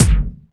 Index of /90_sSampleCDs/Roland L-CDX-01/KIK_Electronic/KIK_Analog K1
KIK BOING08L.wav